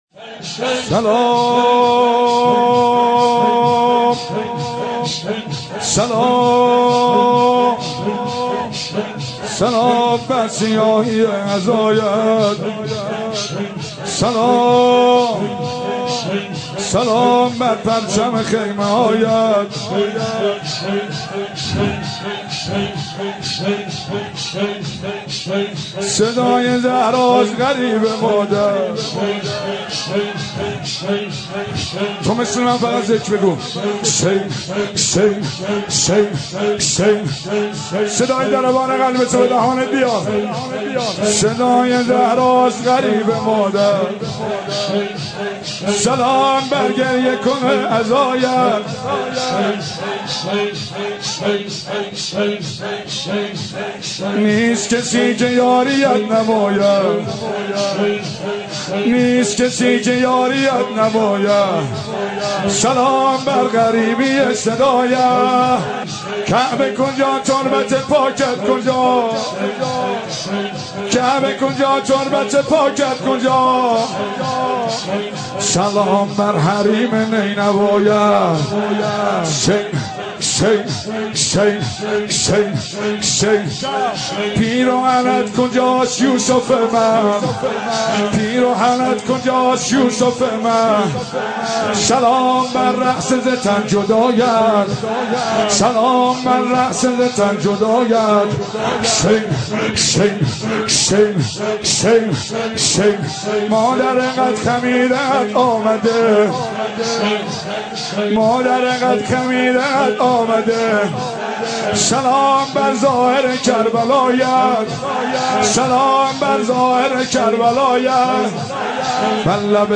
مراسم شب دهم محرم الحرام ۹۵ برگزار شد.